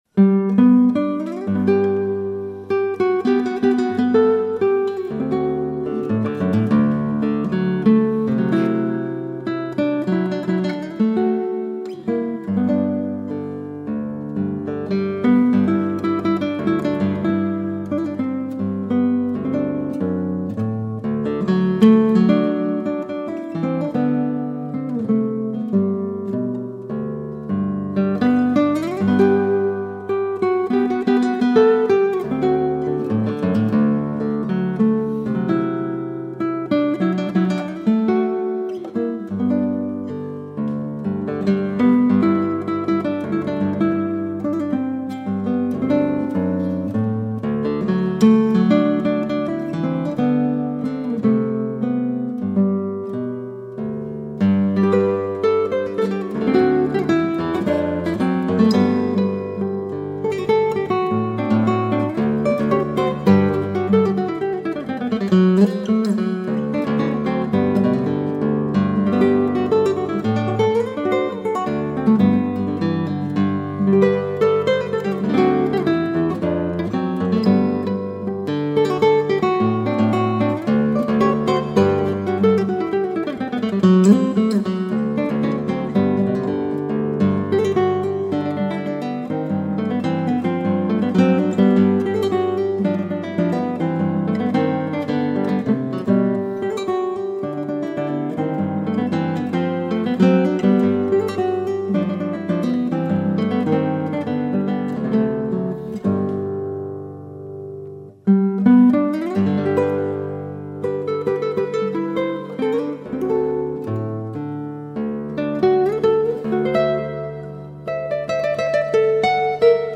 Classical (View more Classical Guitar Music)
classical guitar